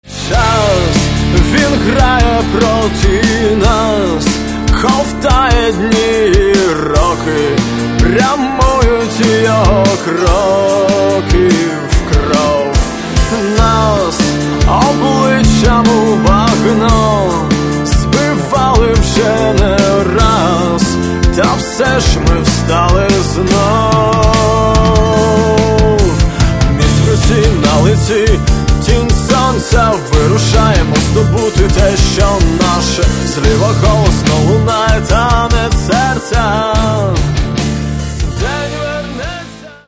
альтернативного фолк-рока